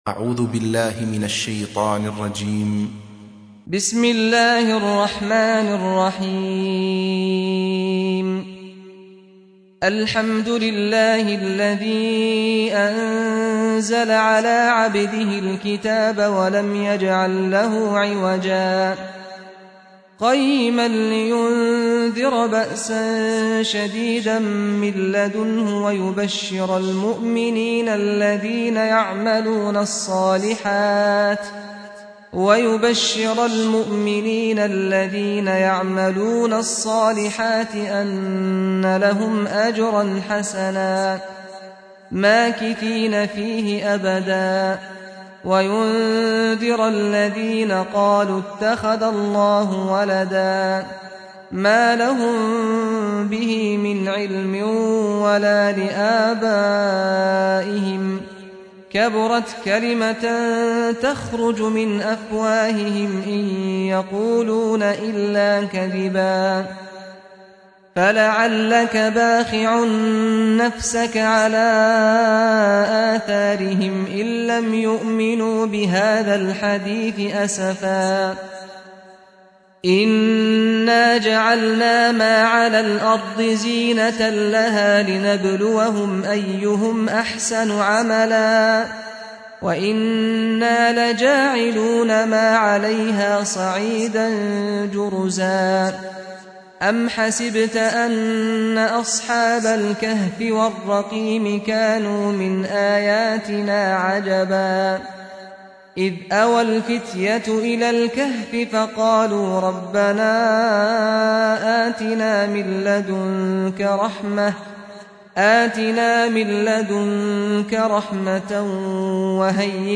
سورة الكهف | القارئ سعد الغامدي